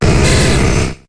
Cri de Magnéti dans Pokémon HOME.